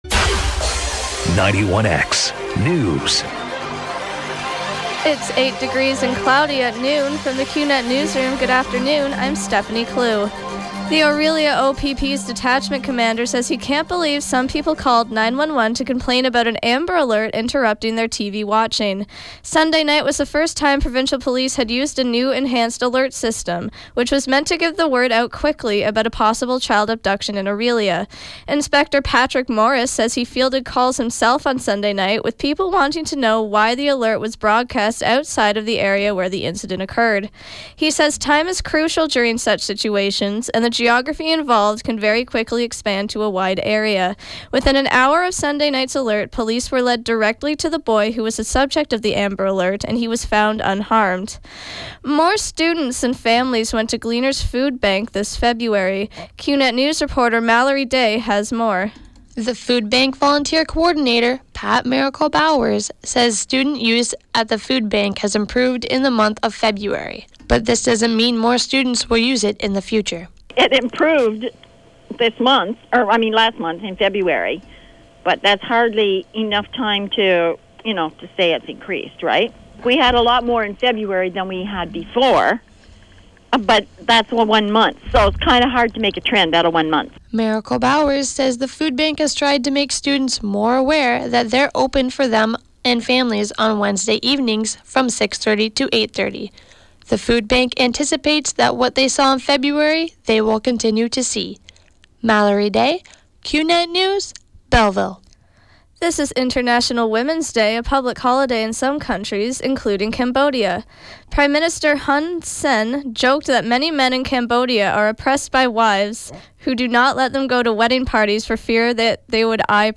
91X Newscast – Tuesday, March 8, 2016, 12 p.m.